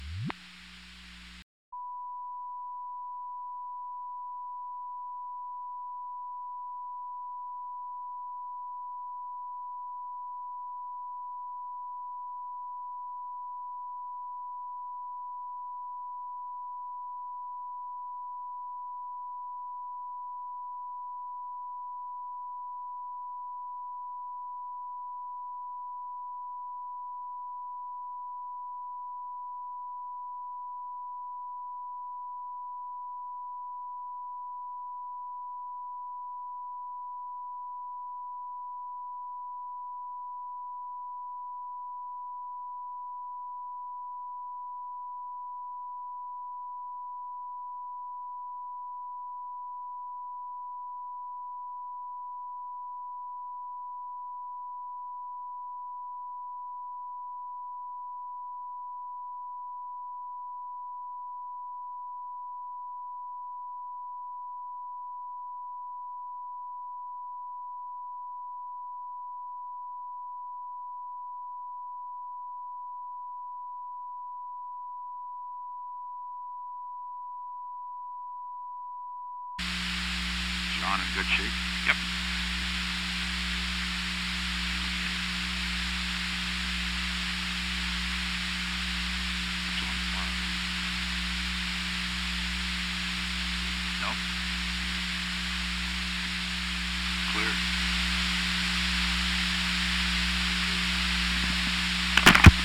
Location: White House Telephone
The President talked with H.R. (“Bob”) Haldeman.